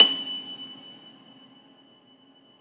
53e-pno24-F5.aif